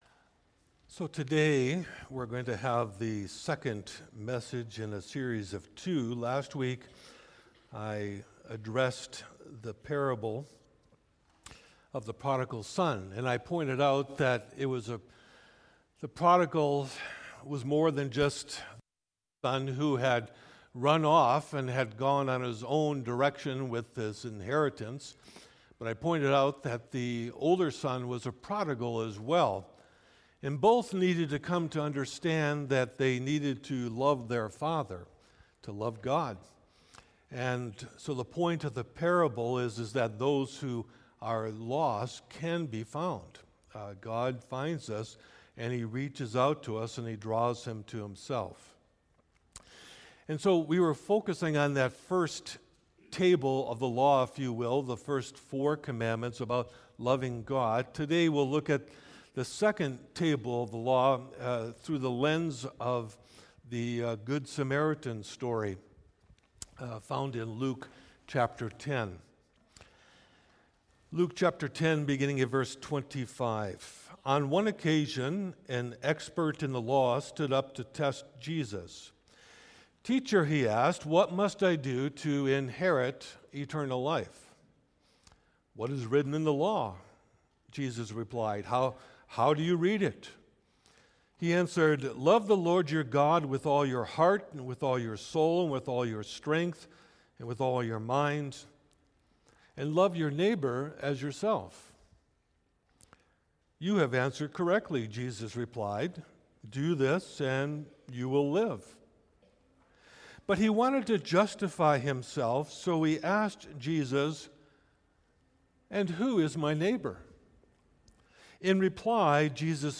Sermons | Ebenezer Christian Reformed Church